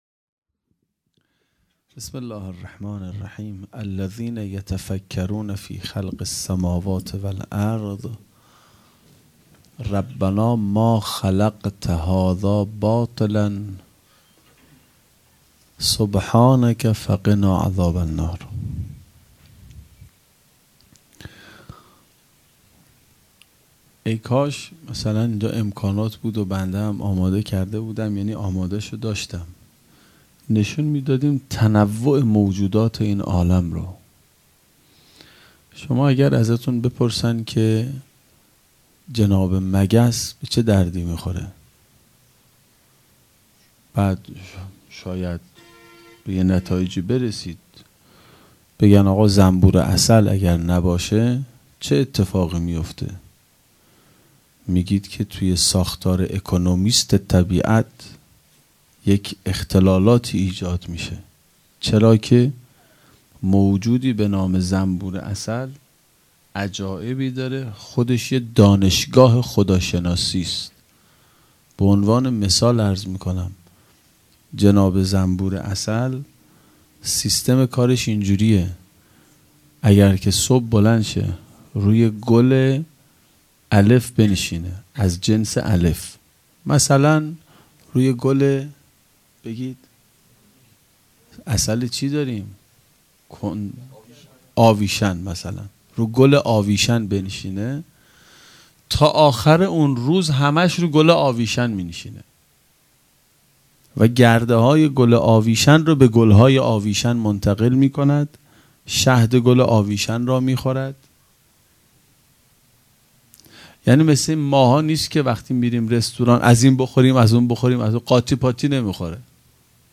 هیئت مکتب الزهرا(س)دارالعباده یزد - موضوع|تفکر و عبادت